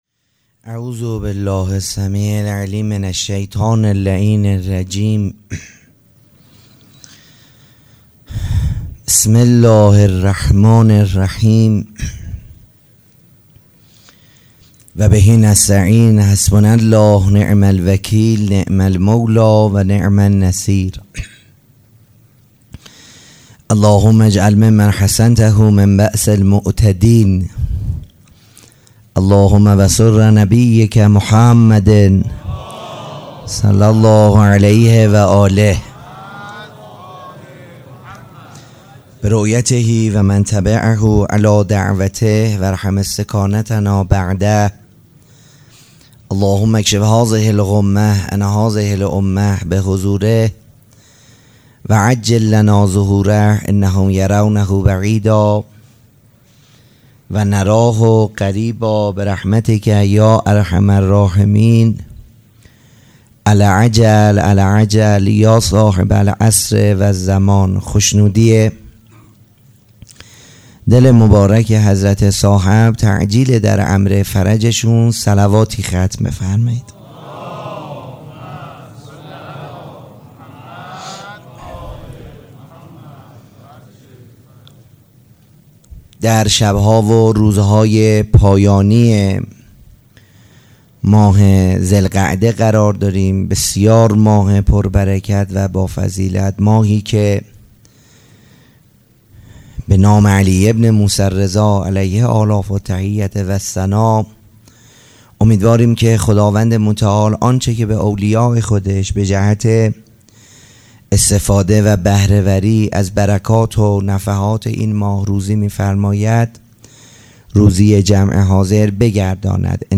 سبک اثــر سخنرانی
سخنرانی.mp3